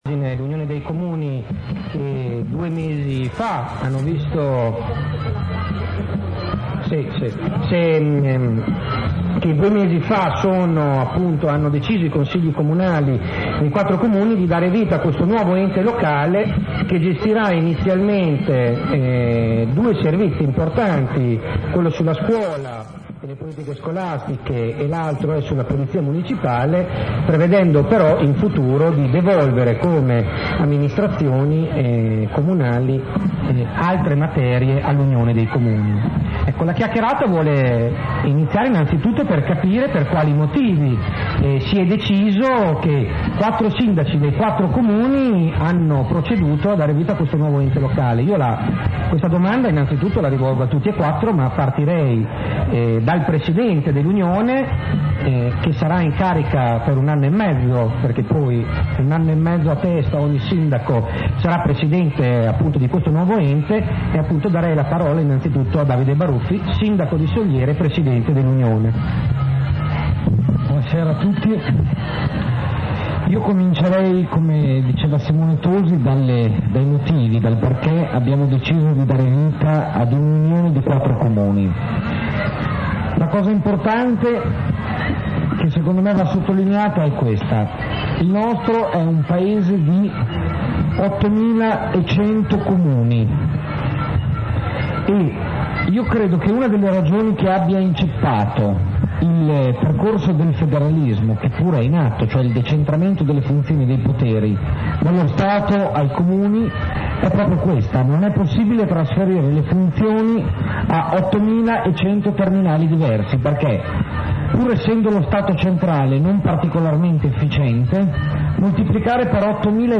Nascita dell' Unione dei Comuni L'unità fà la forza? Discussione con: Enrico Campedelli sindaco di Soliera, Davide Baruffi sindaco di Carpi, Daniela Malavasi sindaco di Novi di Carpi, Stefania Zanni sindaco di Campogalliano